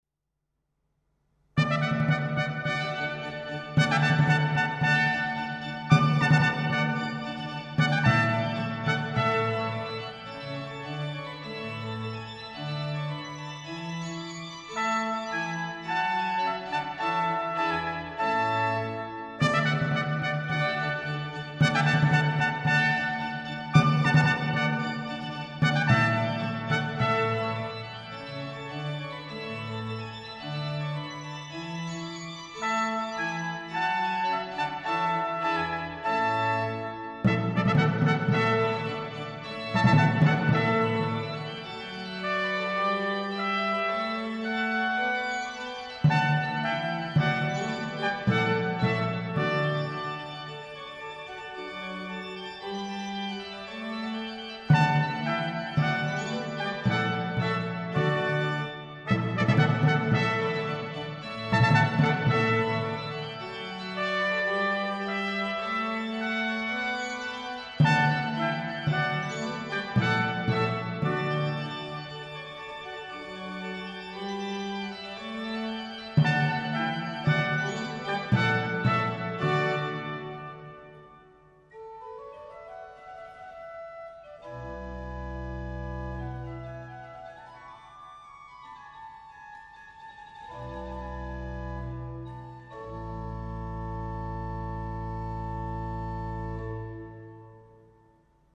Intrada - vivace - gavote - menuet